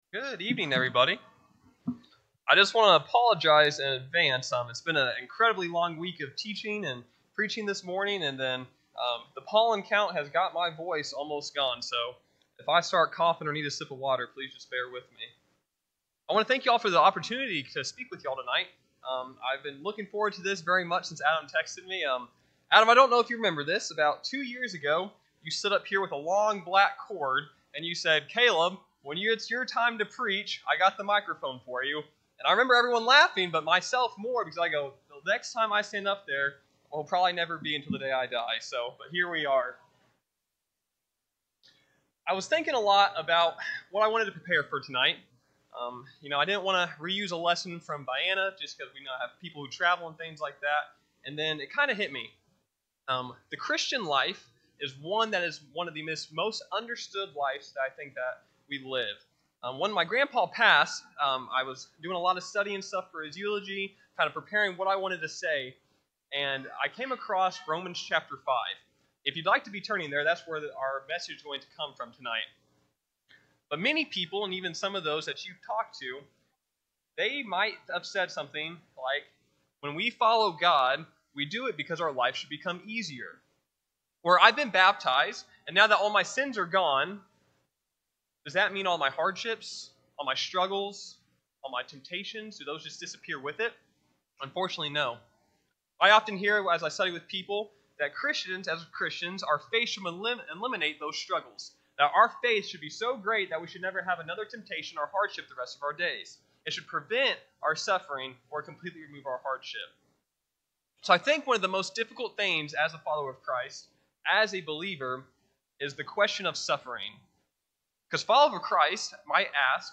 4-19-26-Sunday-PM-Sermon.mp3